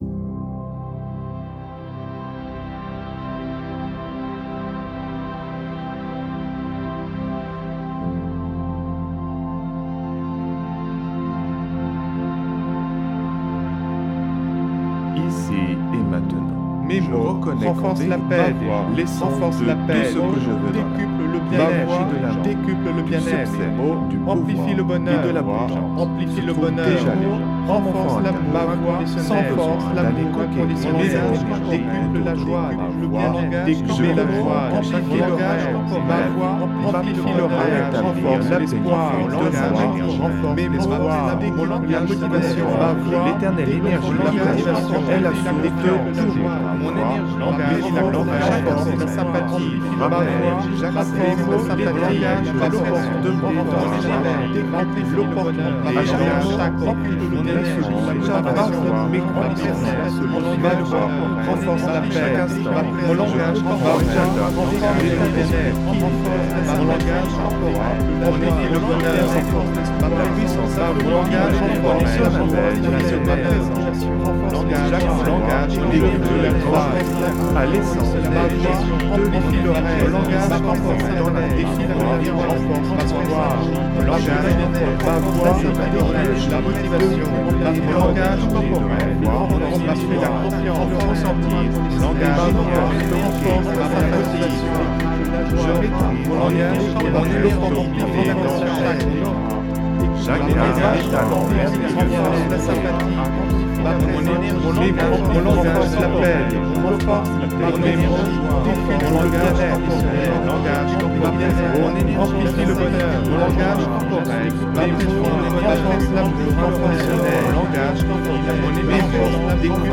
Alliage ingénieux de sons et fréquences curatives, très bénéfiques pour le cerveau.
Pures ondes gamma intenses 96,82 Hz de qualité supérieure. Puissant effet 360° subliminal.